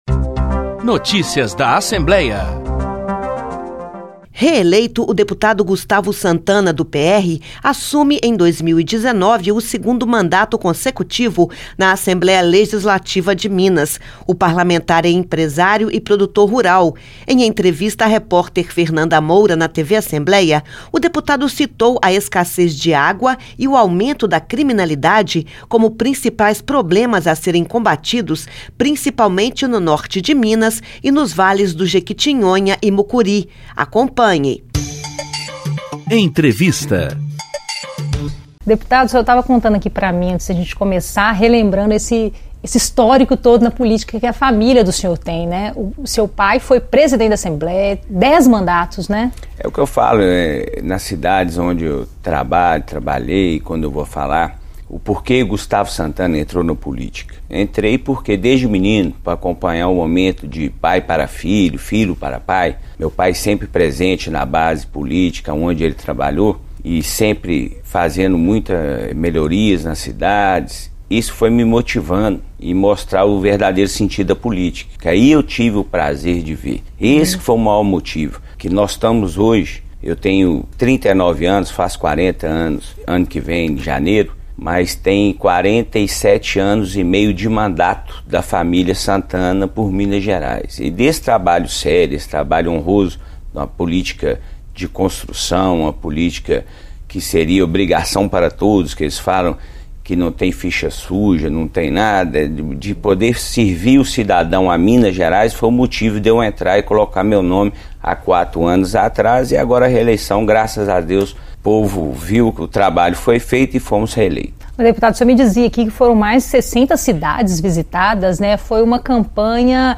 Eleições 2018 - Deputado Gustavo Santana(PR) defende soluções para a escassez de água em Minas